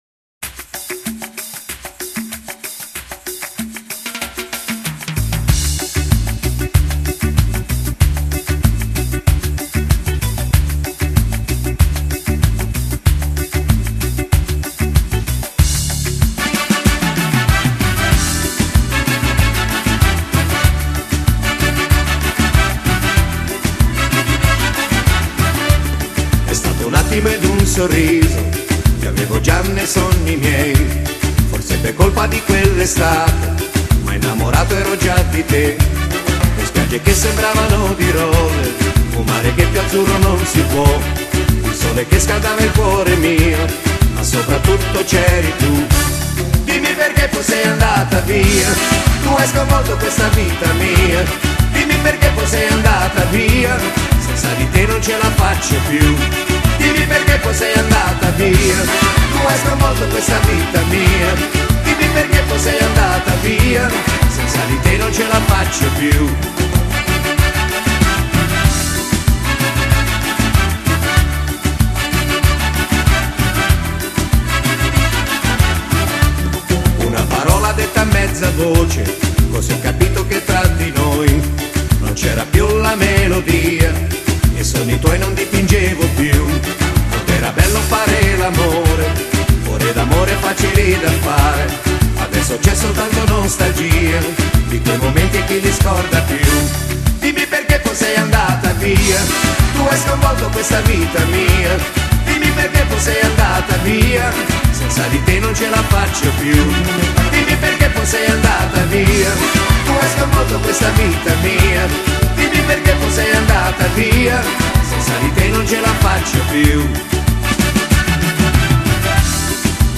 Genere: Meneito